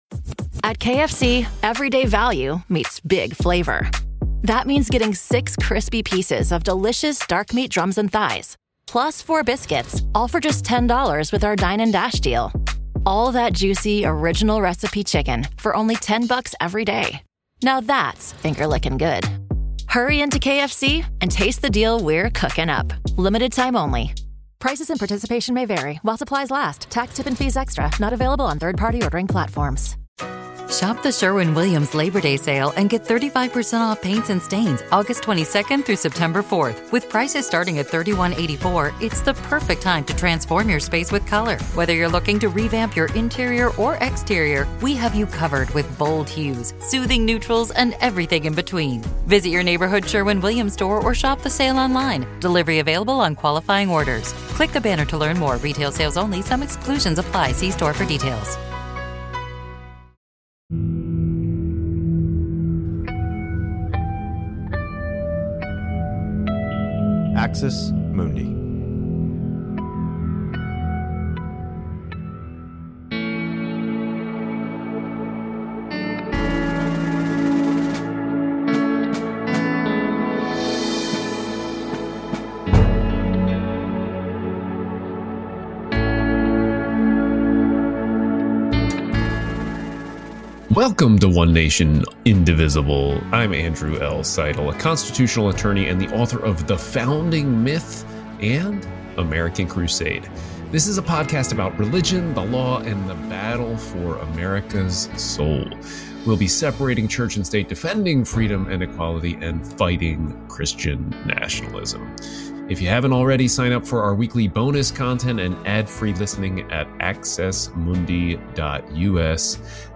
News Talk